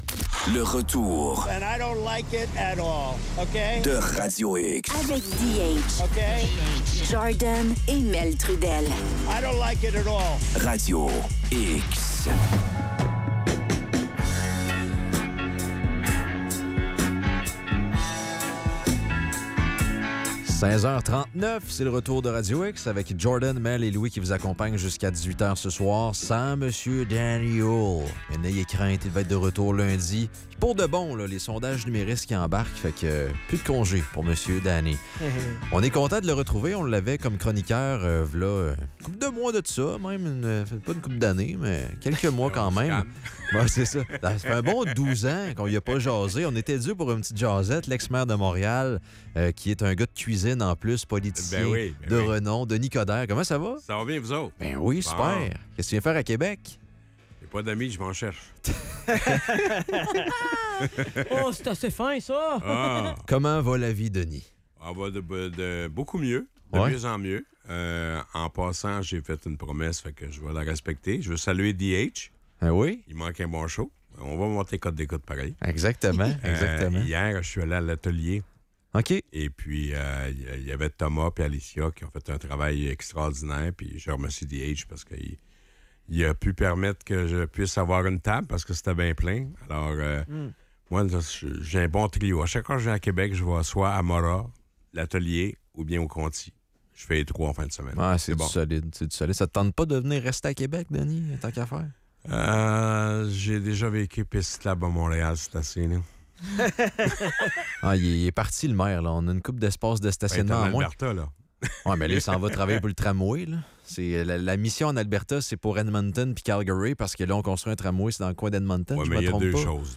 Entrevue avec Denis Coderre.